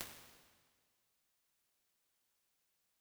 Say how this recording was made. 206-R1_VocalRoom.wav